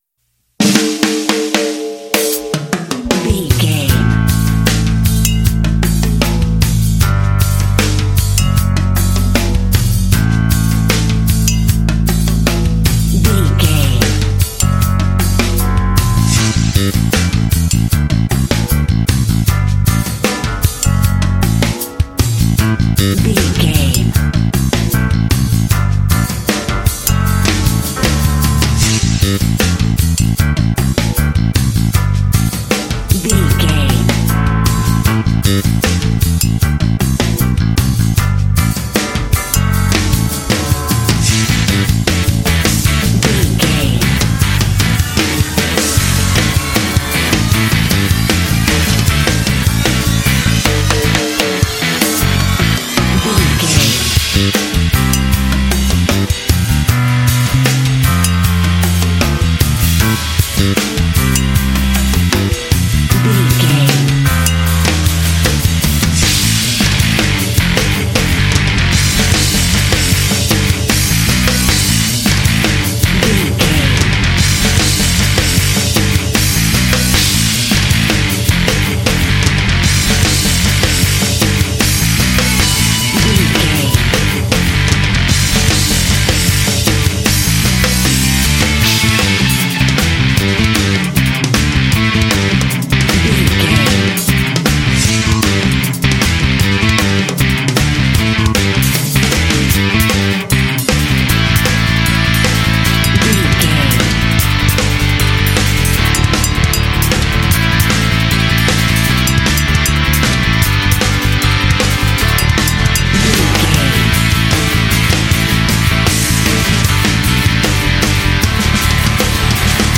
Epic / Action
Dorian
dark
energetic
aggressive
drums
piano
bass guitar
cinematic
symphonic rock